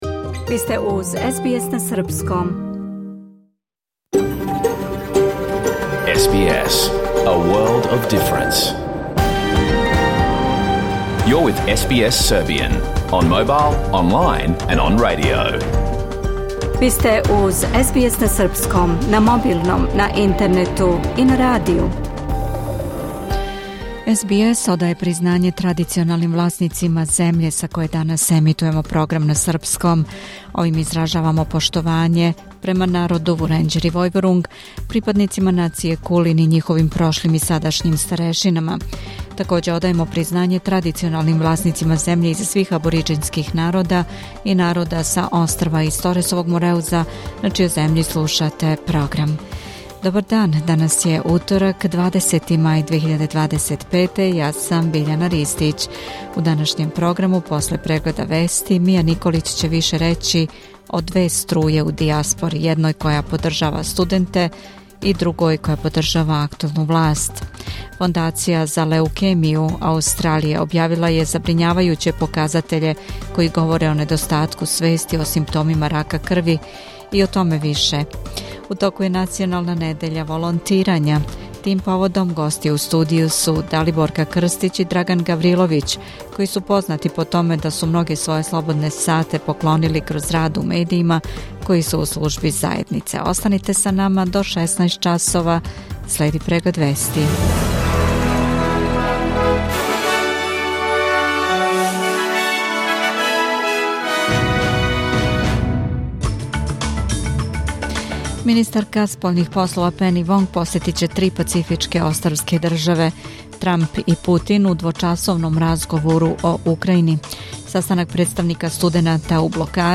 Програм емитован уживо 20. маја 2025. године
Уколико сте пропустили данашњу емисију, можете је послушати у целини као подкаст, без реклама.